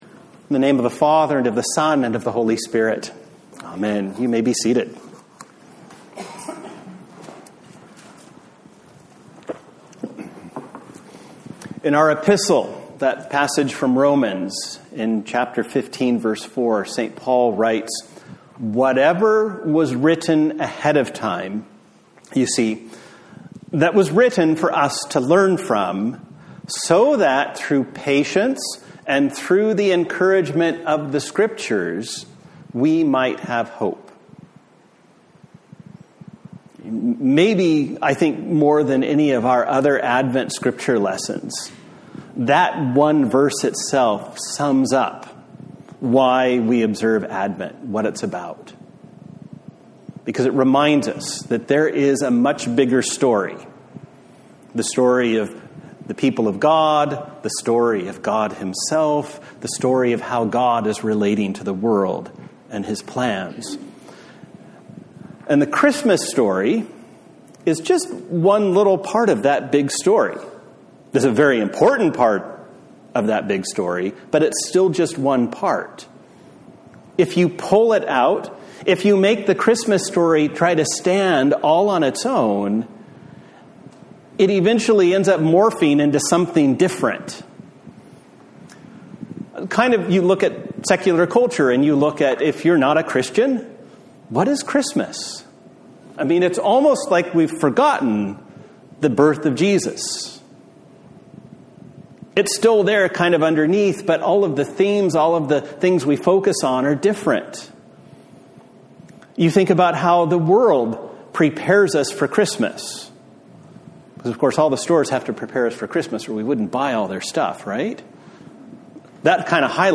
A Sermon for the Second Sunday in Advent